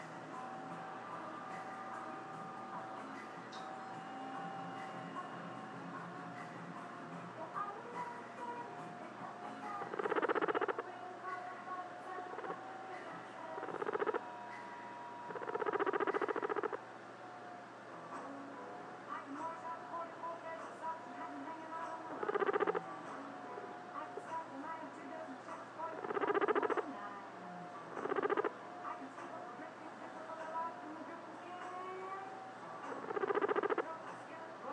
More guinea pig sounds